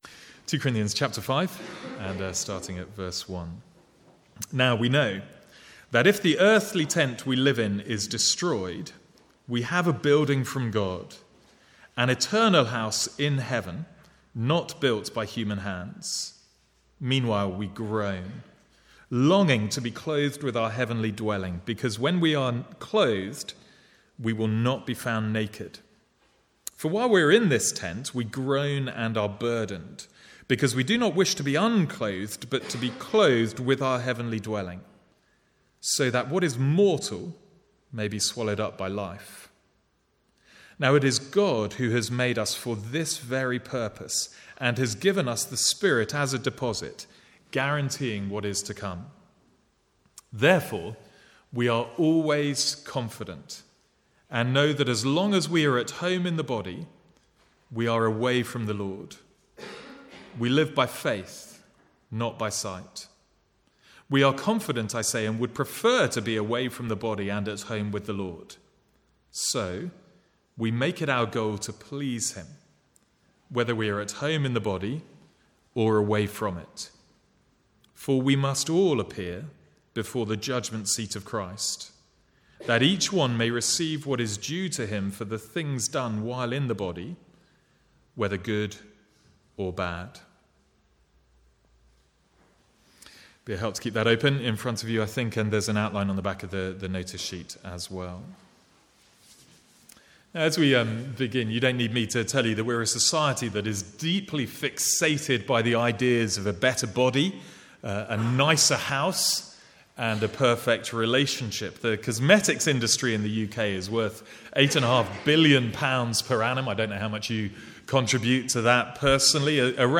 Sermons | St Andrews Free Church
From the Sunday morning series in 2 Corinthians.